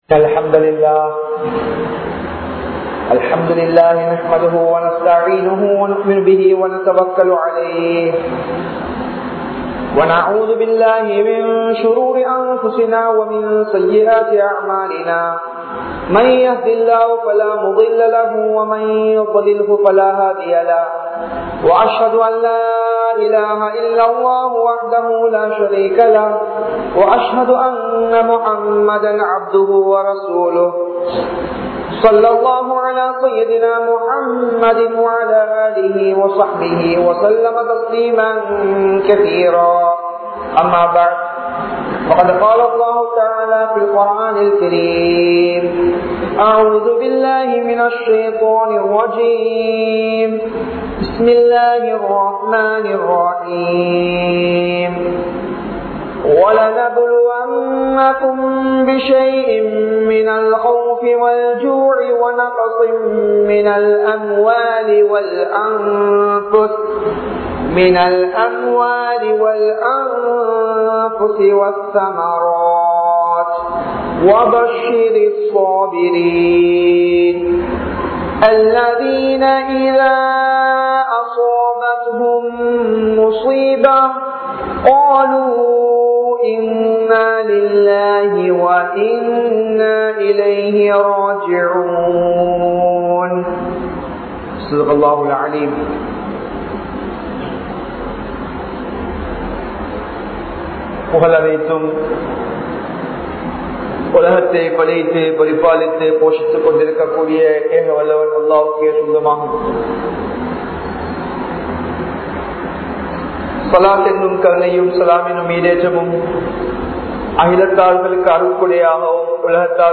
Eeman Oodaha Allah vin Uthaviyai Peruvoam (ஈமான் ஊடாக அல்லாஹ்வின் உதவியை பெறுவோம்) | Audio Bayans | All Ceylon Muslim Youth Community | Addalaichenai
Gothatuwa, Jumua Masjidh